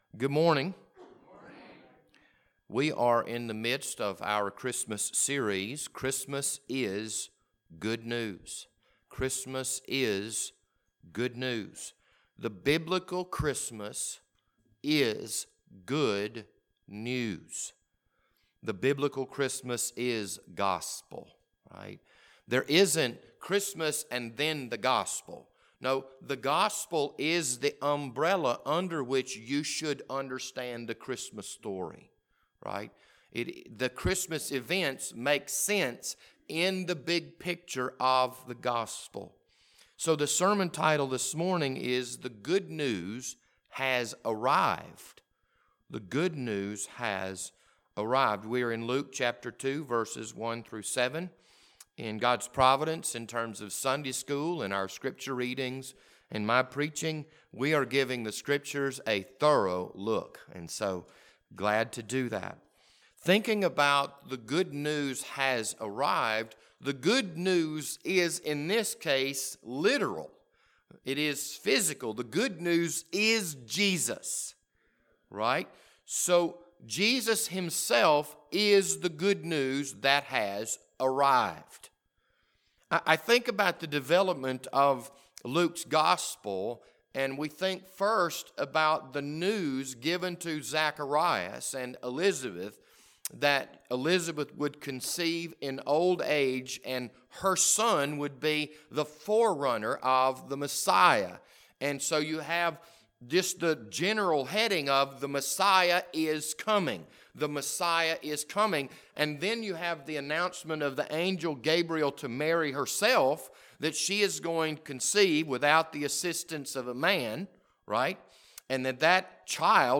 This Wednesday evening Bible study was recorded on January 6th, 2021.